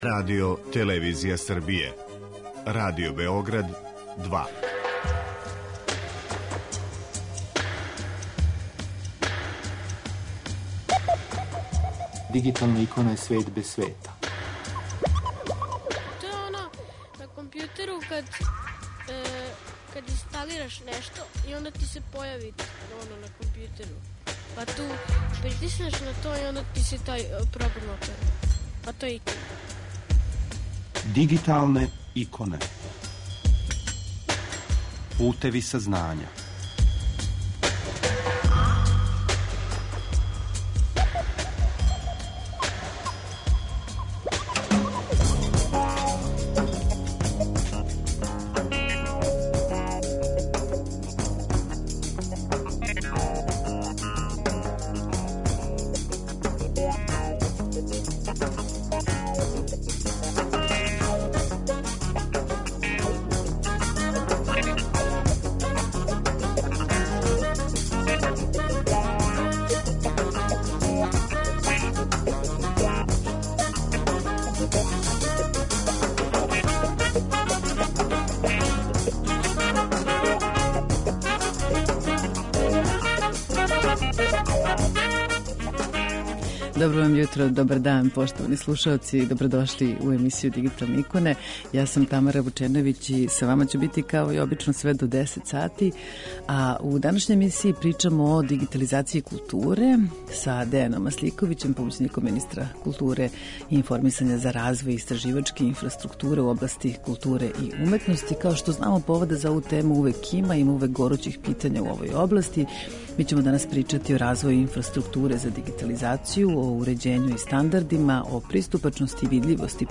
Тема данашње емисије посвећена је дигиталазацији културе, а са нама уживо Дејан Масликовић, помоћник министра културе за развој истраживачке инфраструктуре у области културе и уметности.